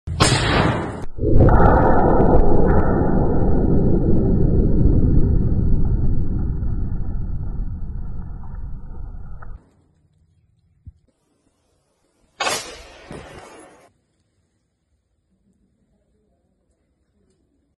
Asmr Bullet Fire #32mm #9mmgoesbang